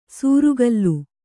♪ sūrugallu